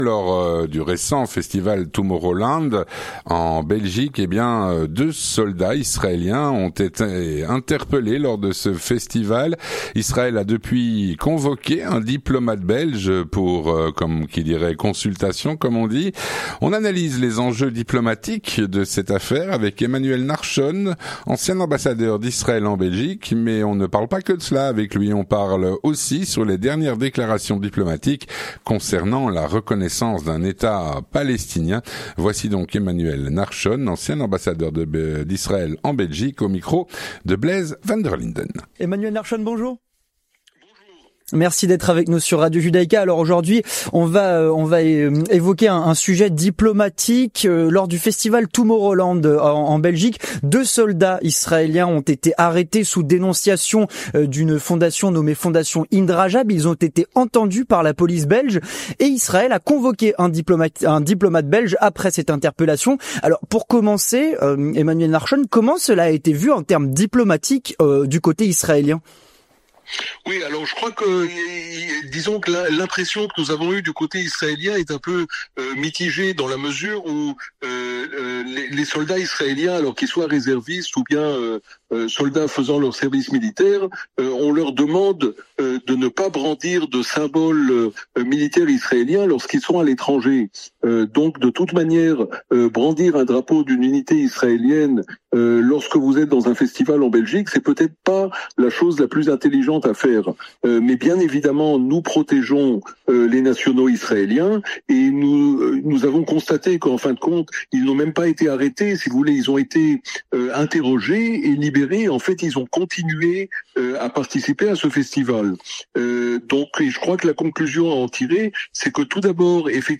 L'entretien du 18H - Israël a convoqué un diplomate belge après l’interpellation de deux soldats israéliens au festival Tomorrowland. Avec Emmanuel Nahshon (31/07/2025)
Nous analysons les enjeux diplomatiques de ces affaires avec Emmanuel Nahshon, ancien ambassadeur d'Israël en Belgique.